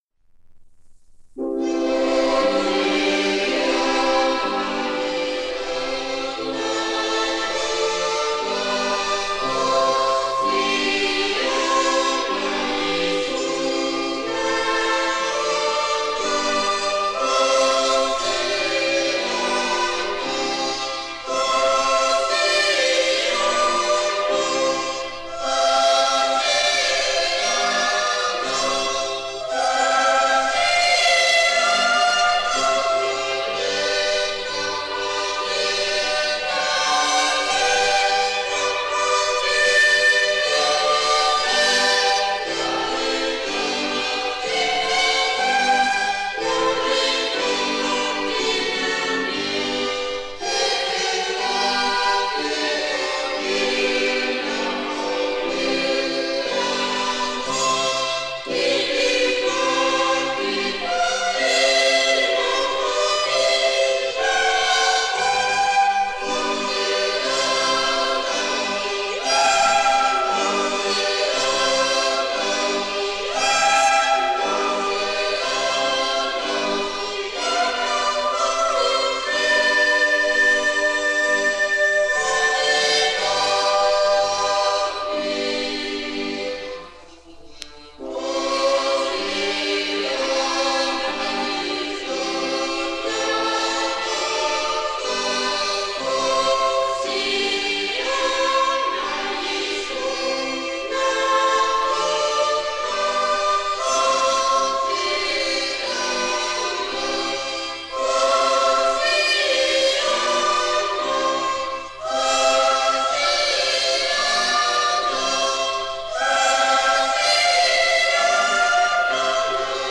SSAB // String 4tet // Organ -or-
Lebhaft; Common time; CM; 53 mm
Performer: Nain Moravian Choir
Location: Moravian Church, Nain, Labrador